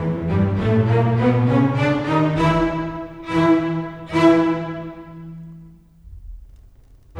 Rock-Pop 20 Bass, Cello _ Viola 04.wav